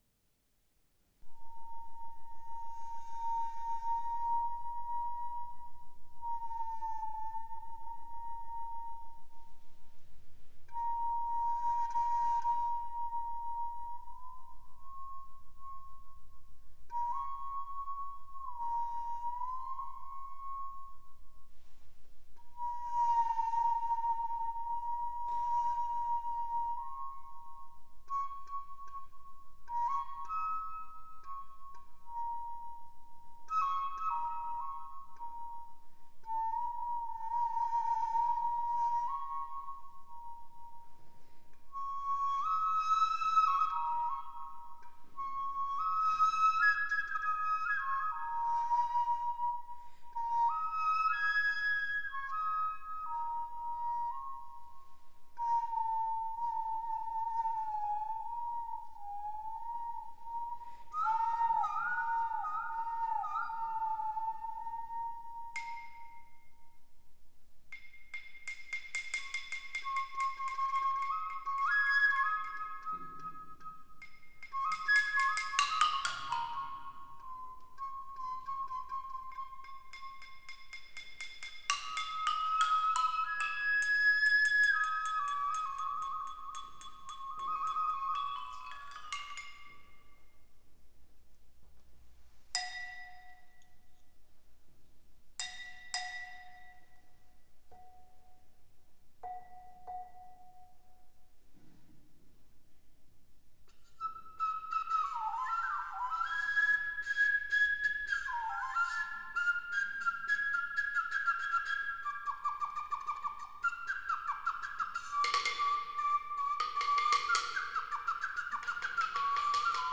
Flötenmusik
Improvisationen auf paläolithischen Instrumenten : Flöten und Lithophon (Klangsteine)